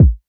Kicks
Dre Kick (C).wav